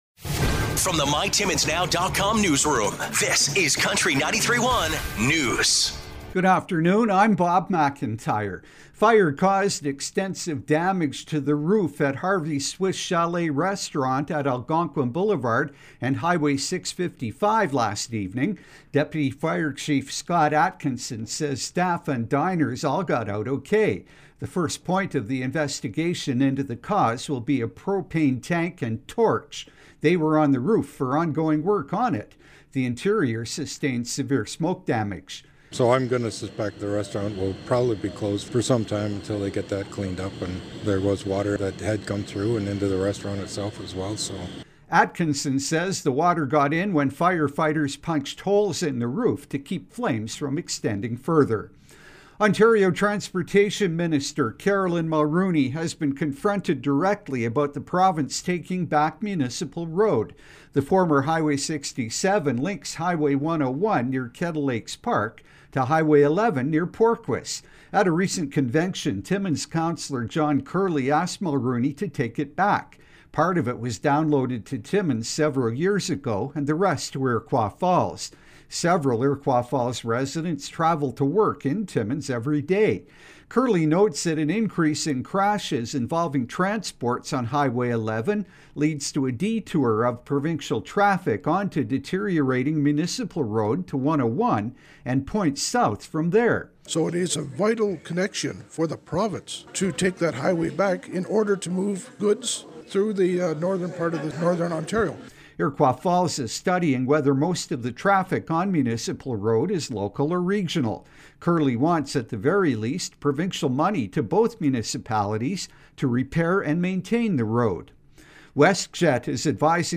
5:00pm Country 93.1 Newscast – Thurs., May 18, 2023